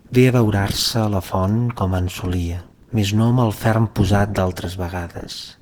speech-male_hpsModel
catalan harmonic hps hpsModel male residual sinusoidal sms sound effect free sound royalty free Memes